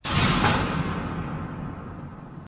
WULA_MW_Breaker_Bar_Hit.wav